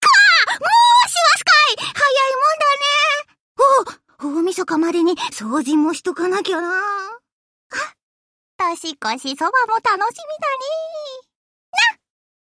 1. She speaks in an Edo dialect.